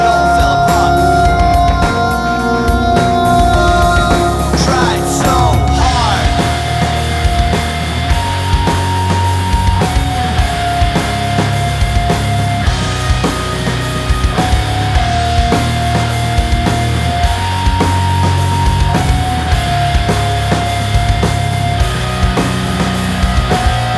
no Backing Vocals Indie / Alternative 3:36 Buy £1.50